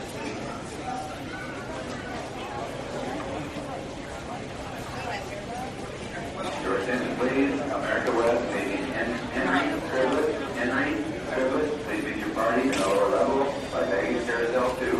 School Hallway
School Hallway is a free ambient sound effect available for download in MP3 format.
398_school_hallway.mp3